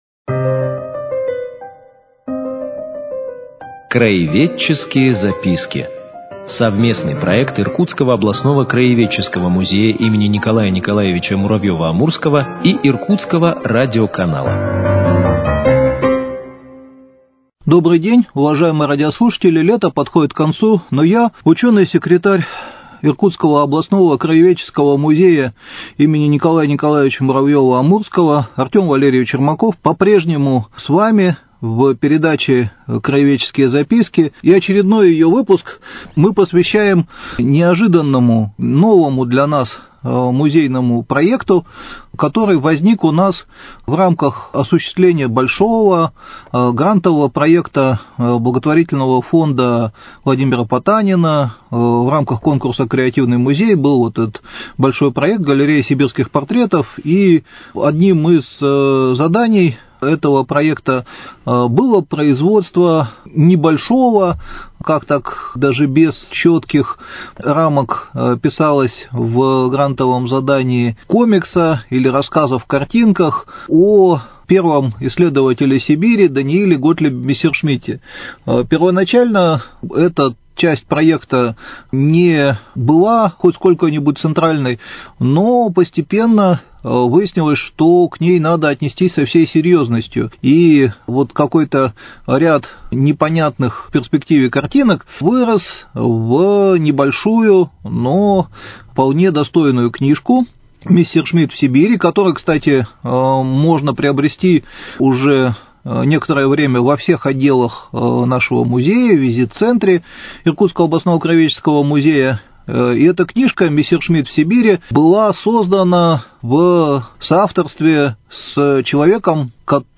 Цикл передач – совместный проект Иркутского радиоканала и Иркутского областного краеведческого музея им. Н.Н.Муравьёва - Амурского.